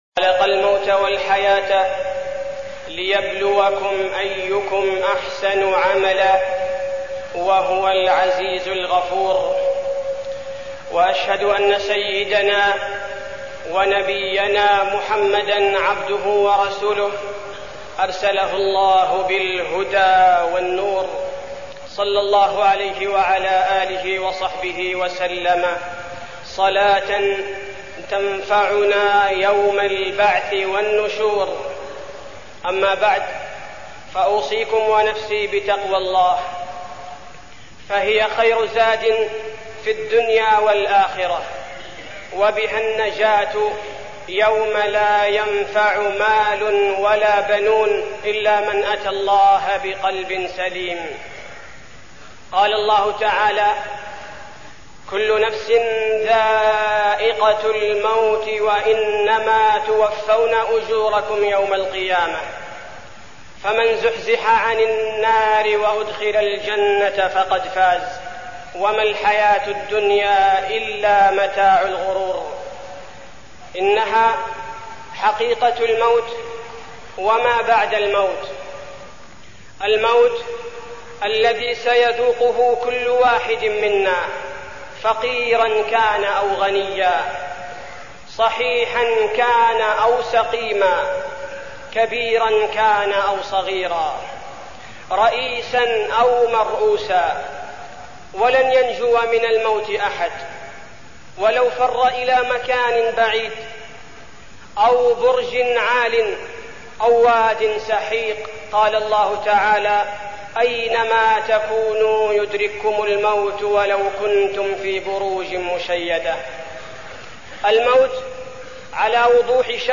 تاريخ النشر ٩ جمادى الآخرة ١٤١٨ هـ المكان: المسجد النبوي الشيخ: فضيلة الشيخ عبدالباري الثبيتي فضيلة الشيخ عبدالباري الثبيتي الموت